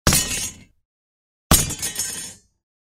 На этой странице собраны звуки катаны и других японских мечей в высоком качестве.
Звук упавшего меча